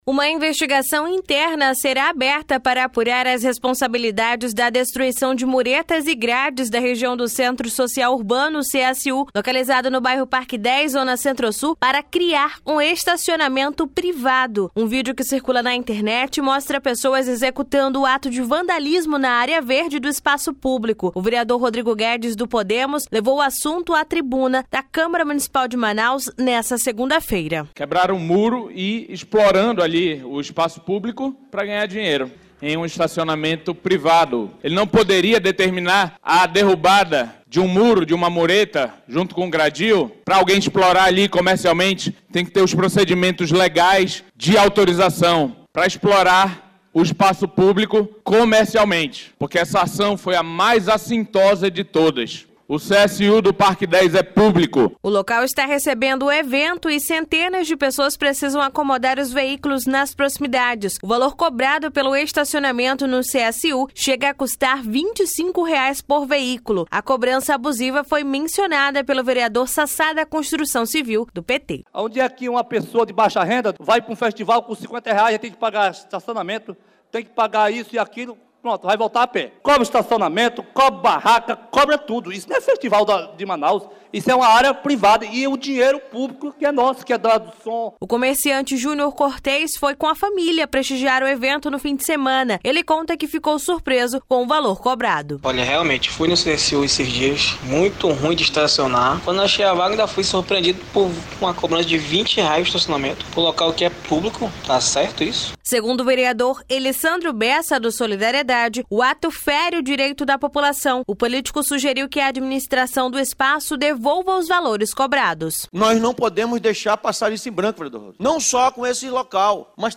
Reportagem
O vereador, Rodrigo Guedes (Podemos), levou o assunto a tribuna da Câmara Municipal de Manaus-CMM nessa segunda-feira, 19. (Ouça)
Segundo o vereador, Elissandro Bessa (Solidariedade), o ato fere o direito da população.